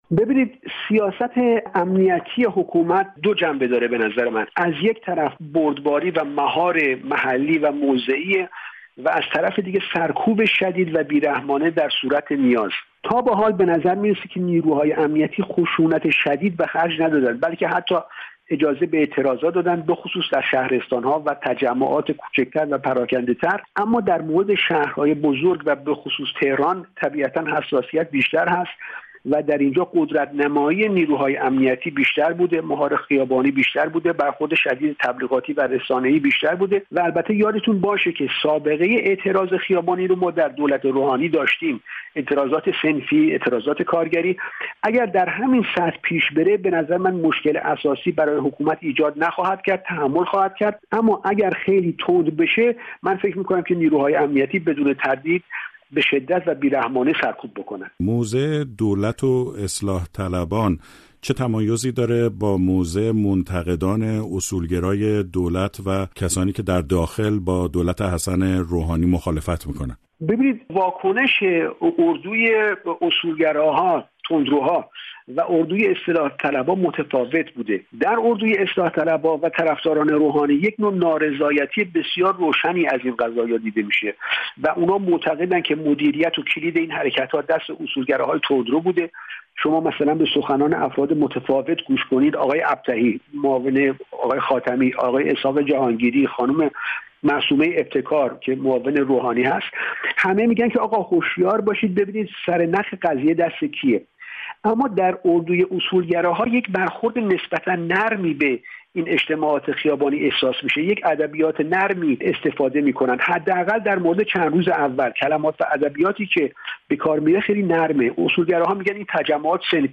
ماهیت اعتراض ها از یک دید جامعه شناسانه سیاسی؛گفتگو